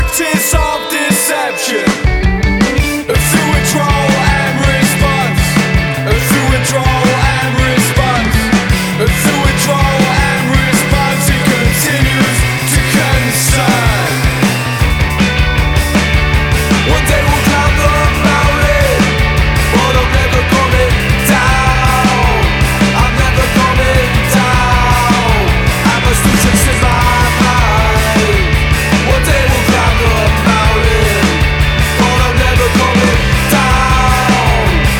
2020-02-21 Жанр: Альтернатива Длительность